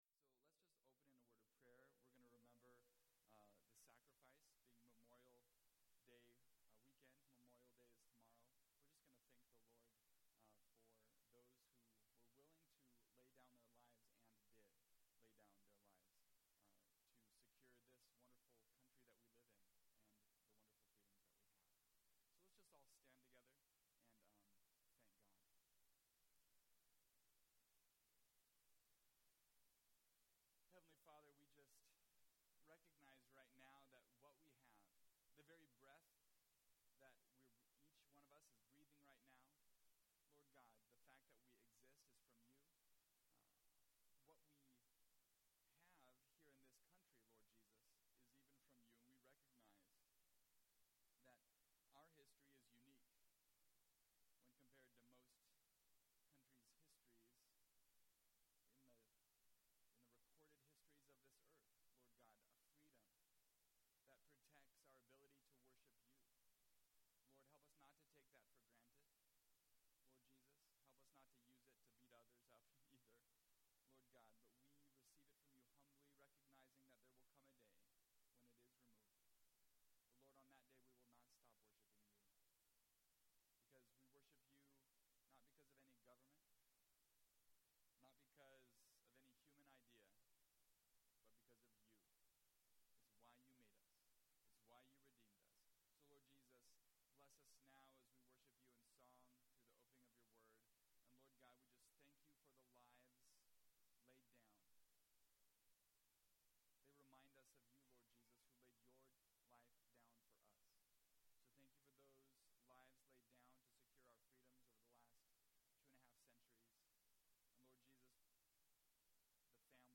Online Church Service